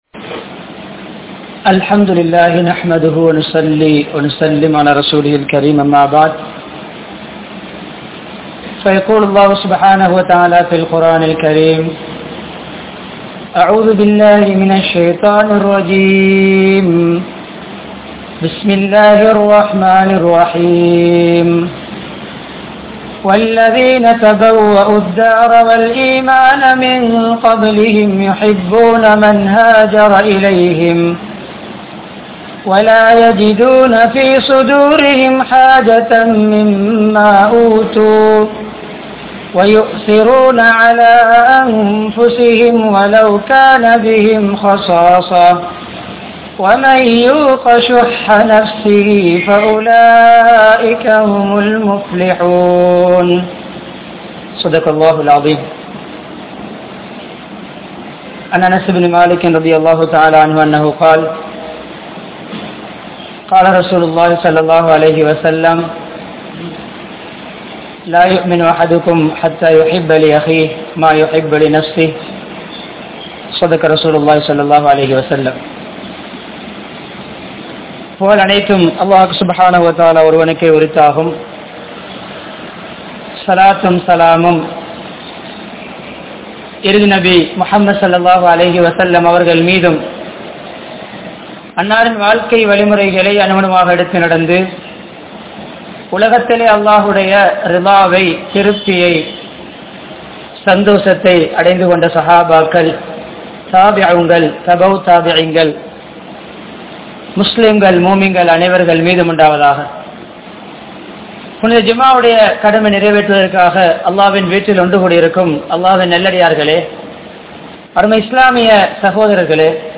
Suyanalaththin Ilivu (சுயநலத்தின் இழிவு) | Audio Bayans | All Ceylon Muslim Youth Community | Addalaichenai
Akurana, Saliheen Jumua Masjidh